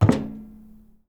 grunk / assets / sfx / footsteps / metal / metal3.wav
metal3.wav